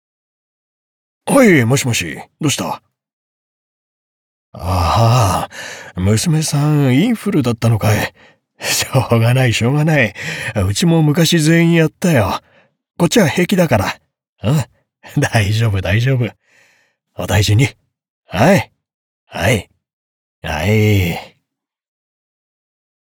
ボイスサンプル
• セリフ：中年男性、優しい、温かみ親しみ